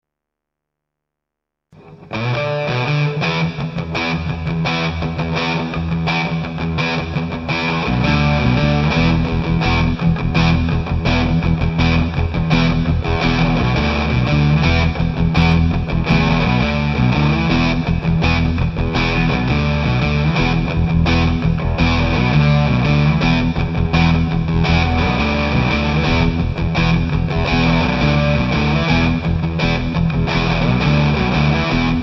Normal channel, fuzz on medium, MRB and a humbucker guitar.